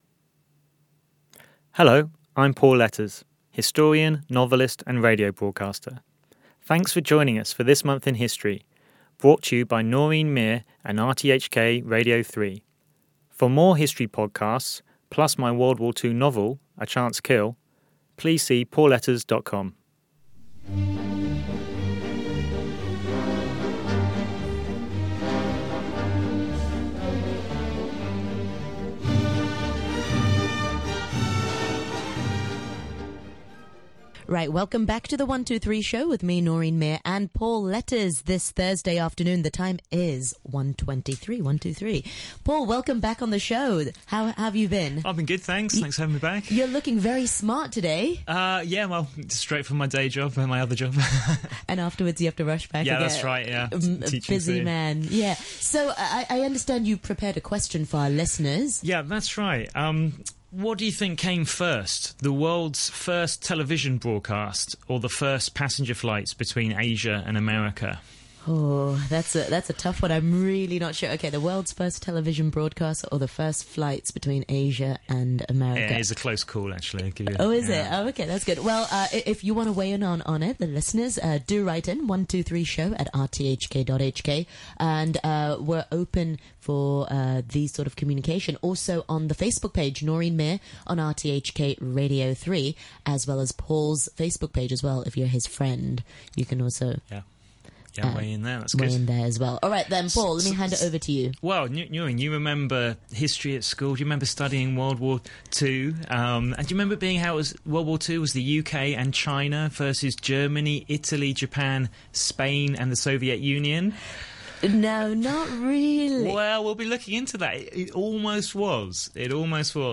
Recorded at Radio Television Hong Kong Studios, Broadcast Drive, Kowloon, Hong Kong.